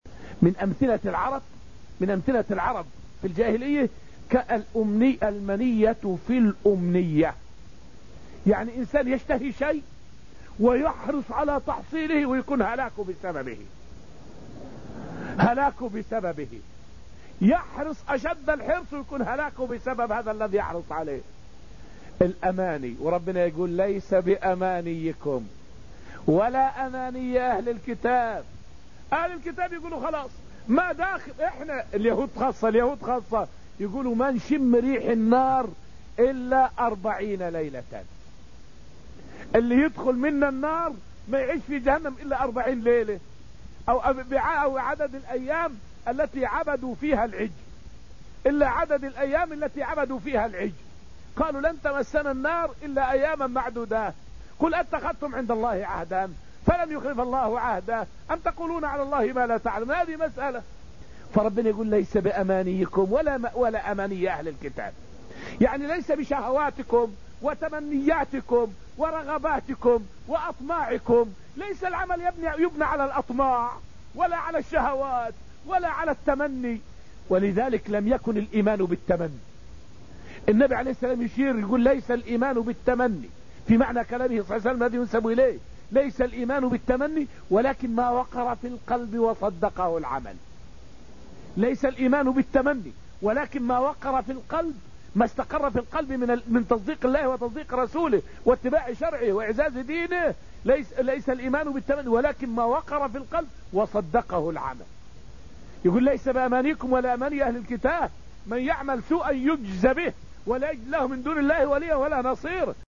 فائدة من الدرس السادس عشر من دروس تفسير سورة الحديد والتي ألقيت في المسجد النبوي الشريف حول معنى قوله تعالى: {ليس بأمانيكم}.